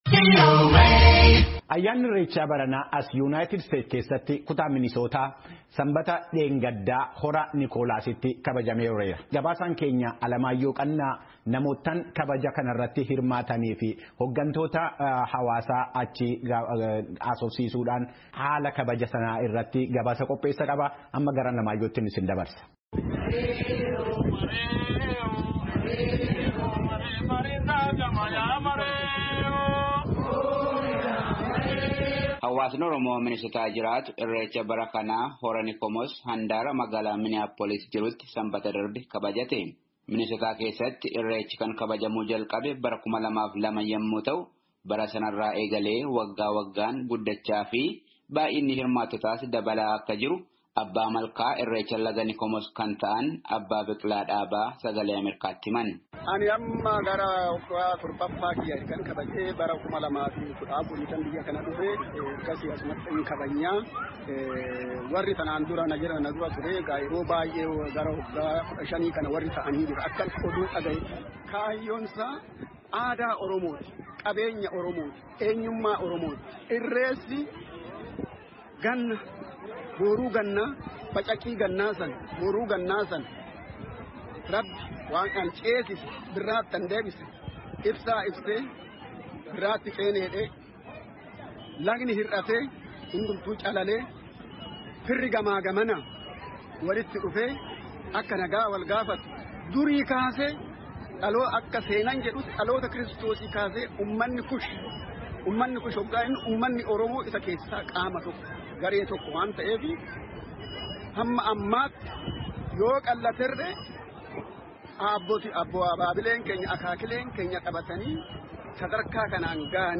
Gabaasaan VOA hirmaattota keessaa haga tokko dubbisuudhaan gabaasaa qindeesse caqasaa.